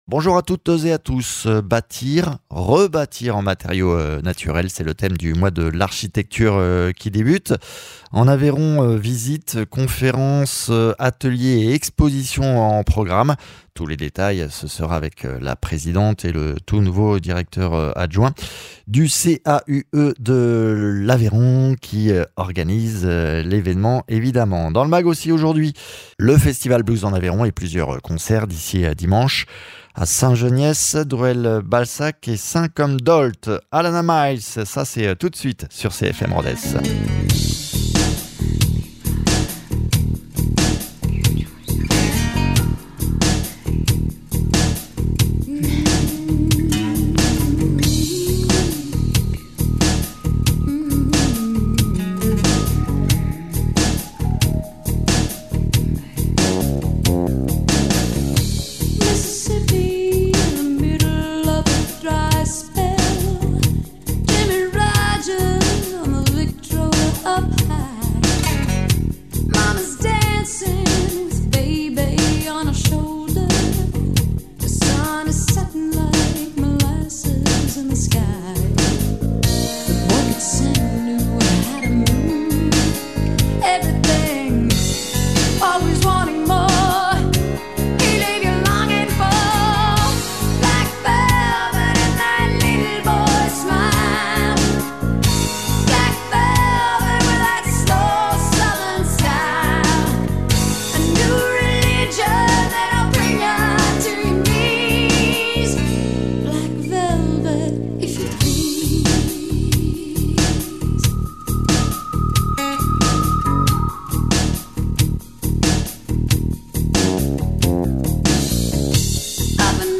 Invité(s) : Valérie Abadie-Roques, élue départementale et Présidente du CAUE de l’Aveyron